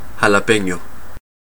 Ääntäminen
Tuntematon aksentti: IPA: [xäläˈpe̞ɲo̞]